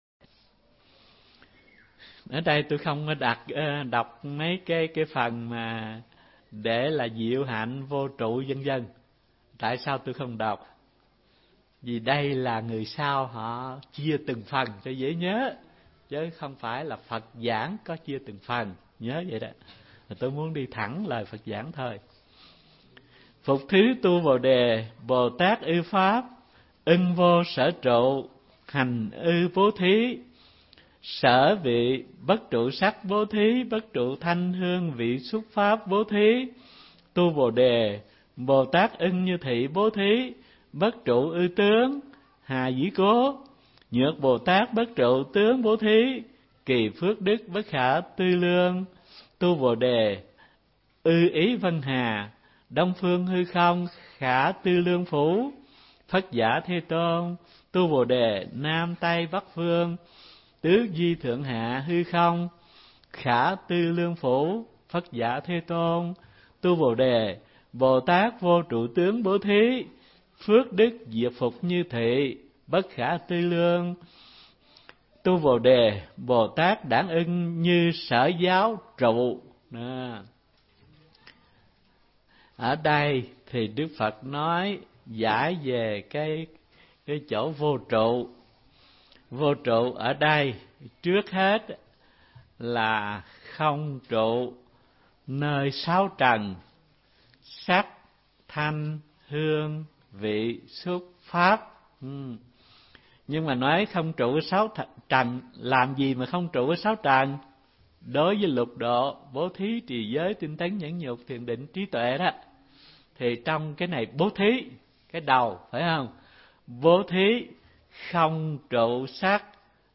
Mp3 Pháp Âm Giảng Kinh Kim Cang 2